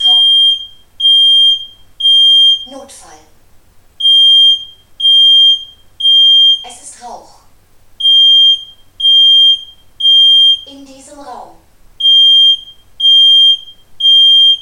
Aus diesem Grund haben wir die Warnmelder Alarme zahlreicher aktueller Modelle für Sie aufgezeichnet.
nest-rauchmelder-alarm.mp3